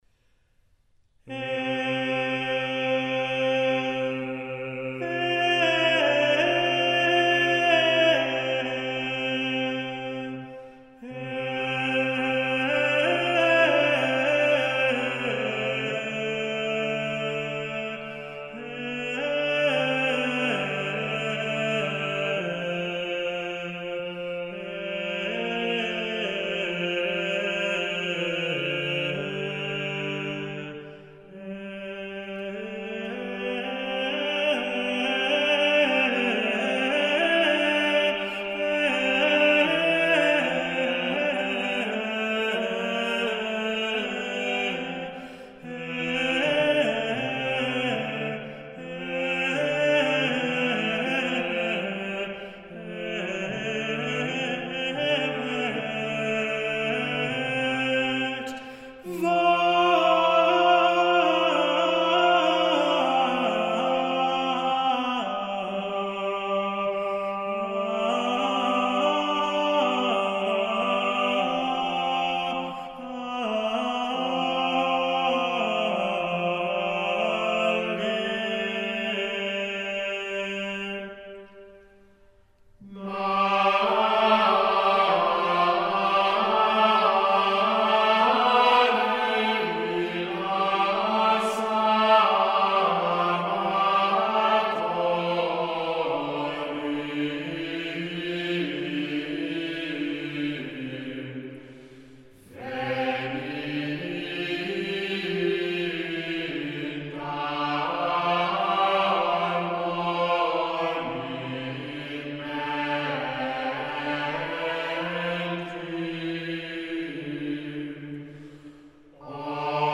Organum
Choral [100%]
Group: A capella